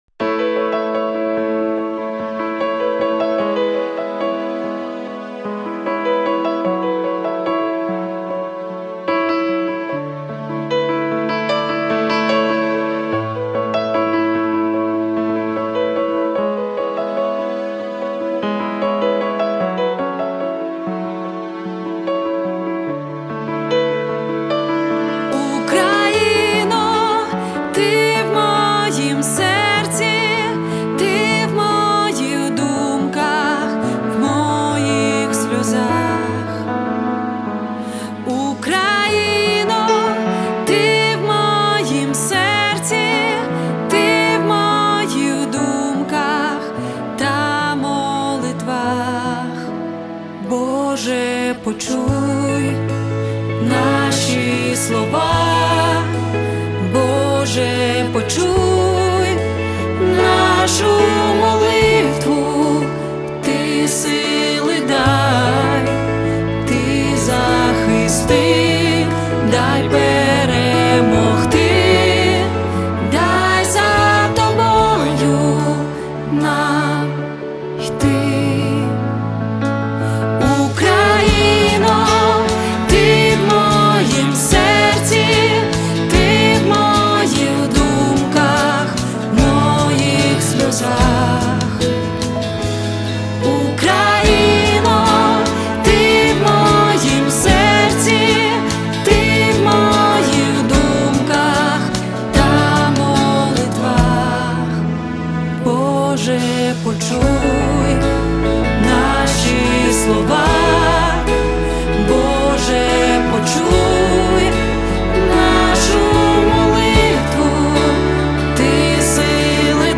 346 просмотров 83 прослушивания 12 скачиваний BPM: 74